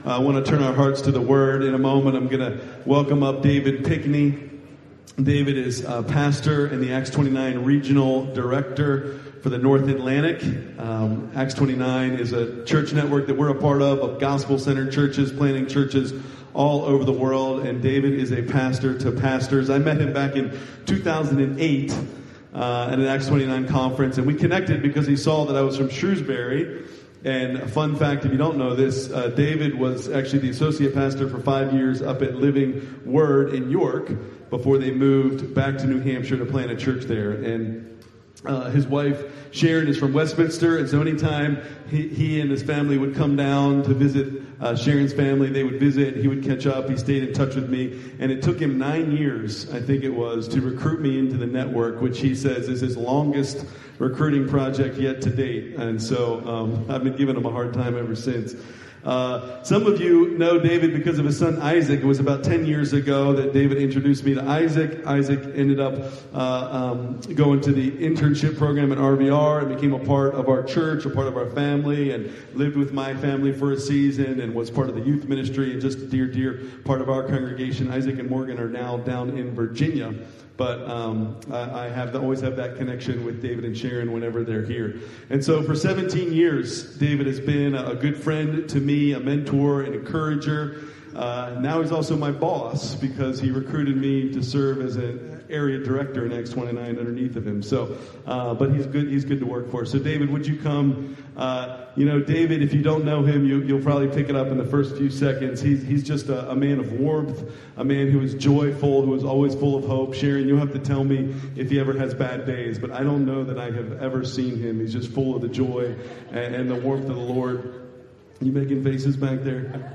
December 14, 2005 Worship Service Order of Service: